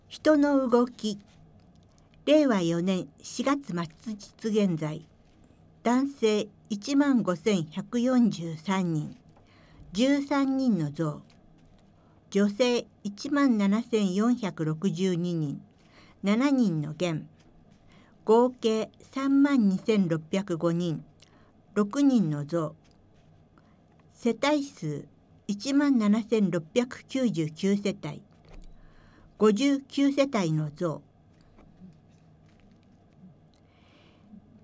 内容をカセットテープに録音し、配布している事業です。
■朗読ボランティア「やまびこ」が音訳しています